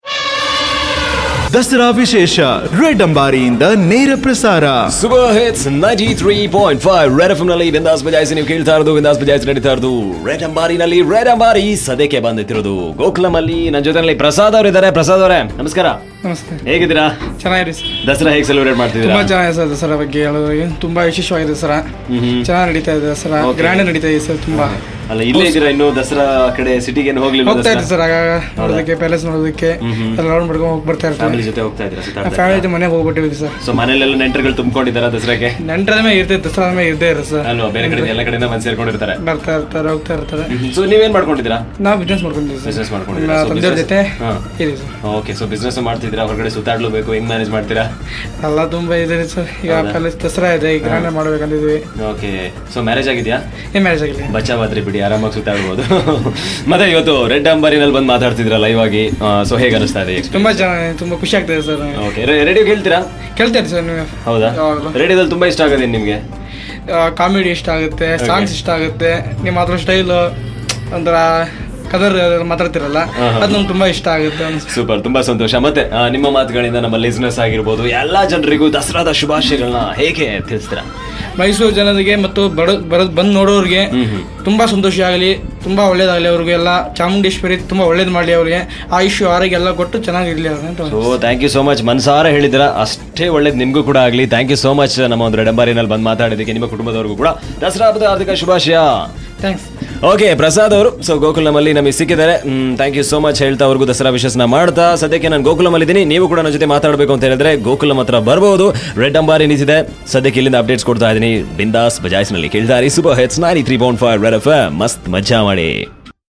Red Ambari is a Studio on wheel where listeners and celebrities share their festive excitement